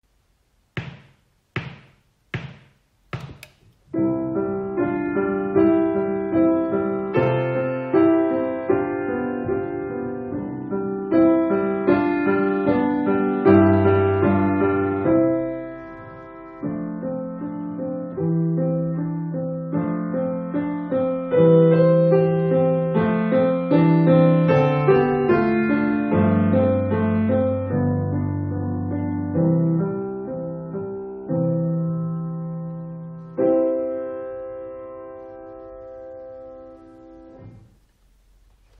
• Grade 2 Teacher Part Recording - Exam Speed